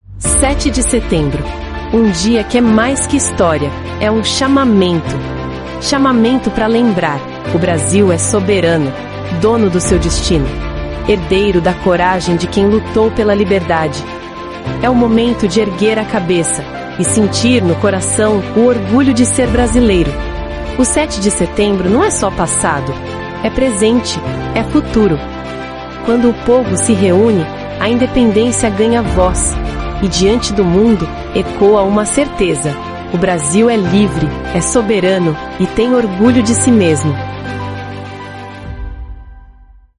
• Spot